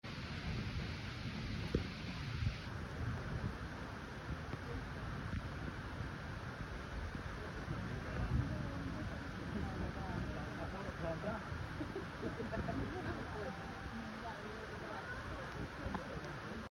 The sun bear is thrilled sound effects free download
The sun bear is thrilled sound effects free download By sunbearbsbcc 1173 Downloads 1 weeks ago 16 seconds sunbearbsbcc Sound Effects About The sun bear is thrilled Mp3 Sound Effect The sun bear is thrilled by the rain after several hot days here, happily climbing to enjoy the cool weather.